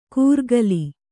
♪ kūrgali